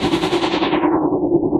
Index of /musicradar/rhythmic-inspiration-samples/150bpm
RI_ArpegiFex_150-02.wav